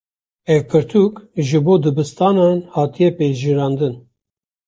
Pronunciado como (IPA)
/pɛʒɪɾɑːnˈdɪn/